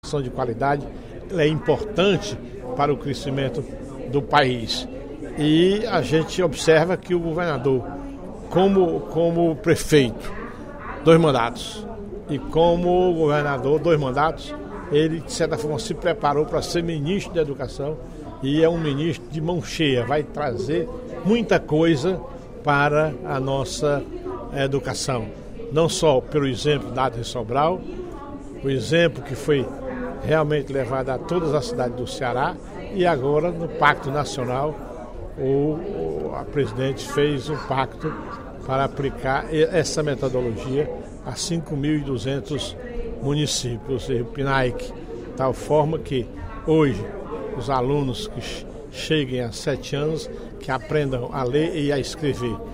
O deputado Professor Teodoro (PSD) elogiou, no primeiro expediente da sessão plenária desta terça-feira (10/02), a entrevista concedida pelo ministro da Educação, Cid Gomes, ao programa Bom dia Brasil da TV Globo, exibida em 5 de janeiro deste ano.